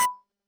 button-click.mp3